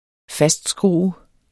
Udtale [ -ˌsgʁuˀə ]